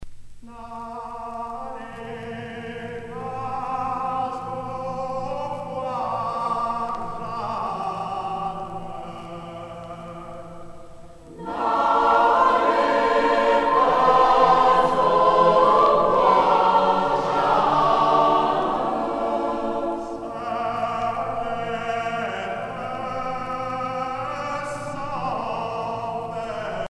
Genre dialogue
(chorale)
Pièce musicale éditée